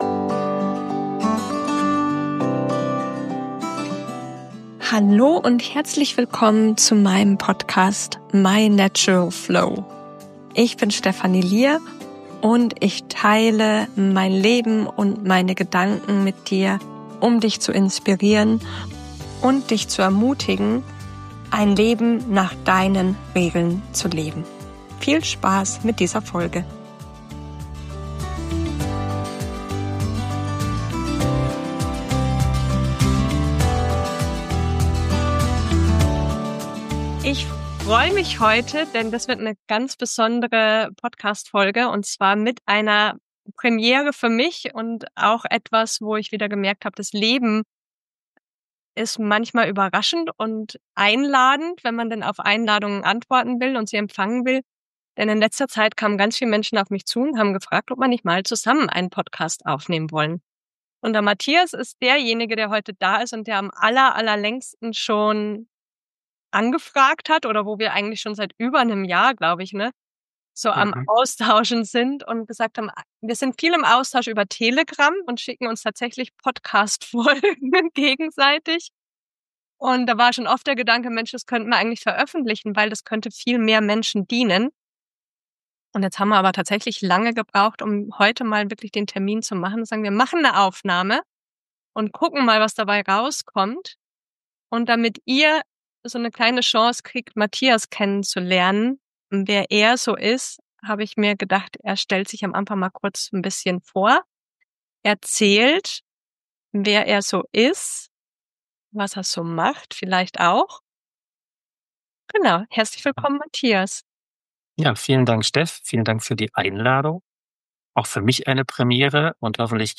Das Leben hat eingeladen und ich habe meinen ersten Gesprächspartner im Podcast zu Gast.